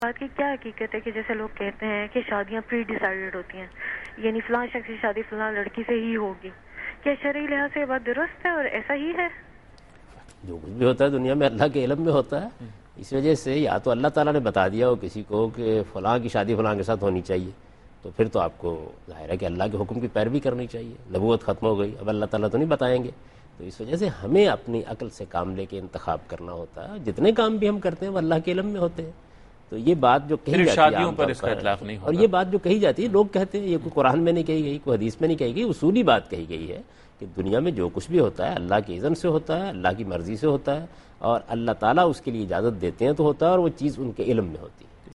TV Programs
Category: TV Programs / Dunya News / Deen-o-Daanish / Questions_Answers /